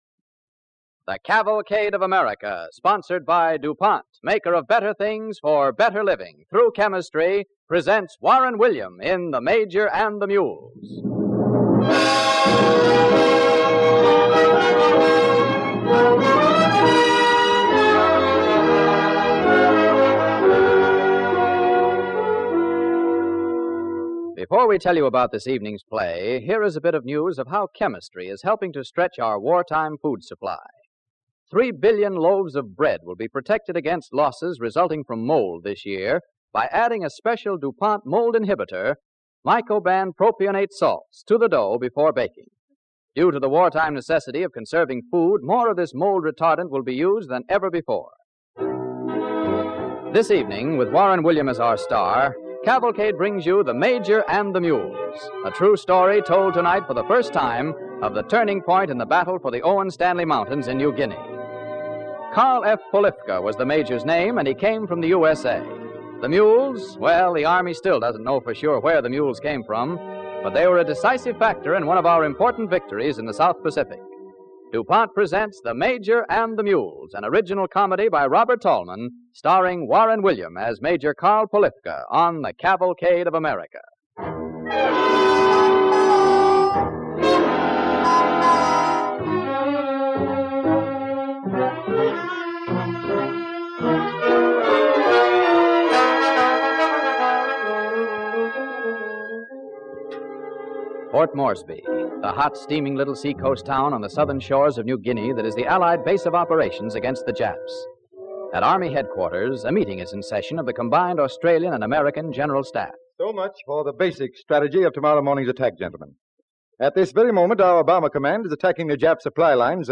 The Major and the Mules, starring Warren William
Cavalcade of America Radio Program